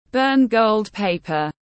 Đốt vàng mã tiếng anh gọi là burn gold paper, phiên âm tiếng anh đọc là /bɜːn ɡəʊld ˈpeɪ.pər/